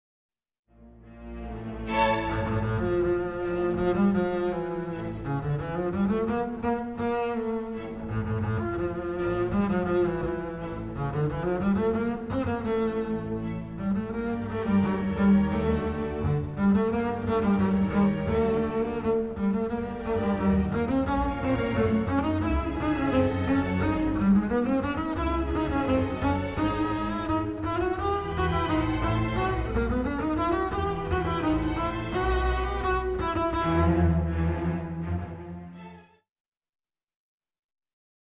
Excerpts are from the orchestra version.